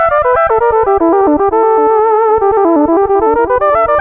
Synth 16.wav